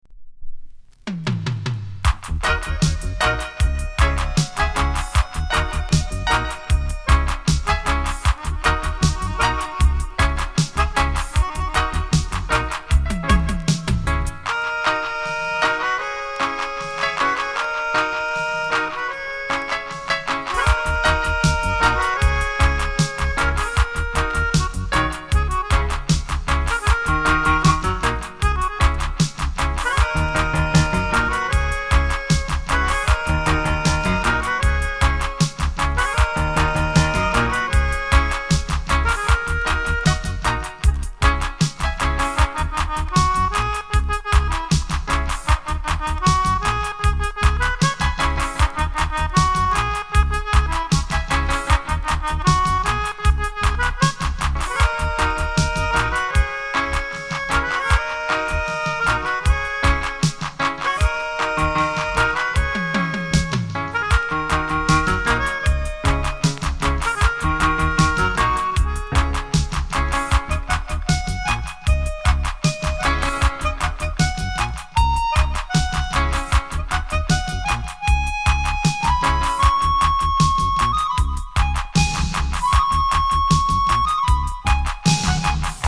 ジャマイカ盤 7inch/45s。